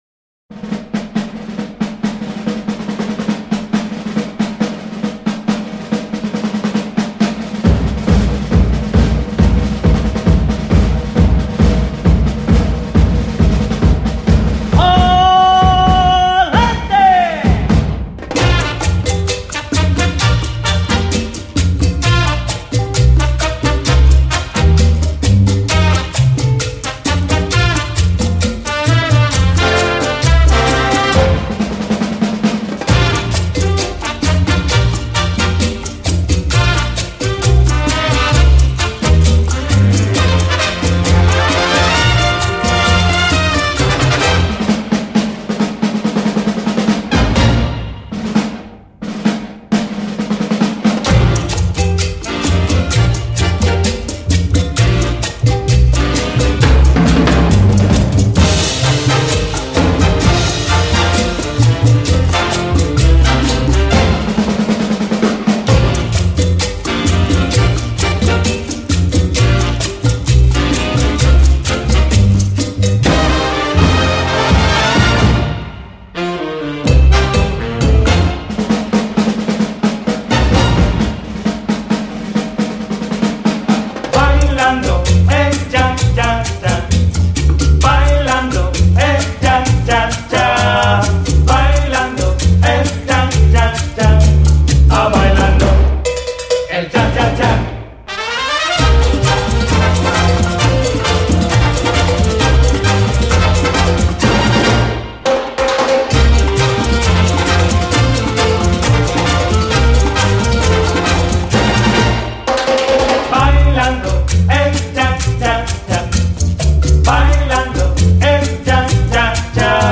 专辑格式：DTS-CD-5.1声道
最动感、奔放的舞曲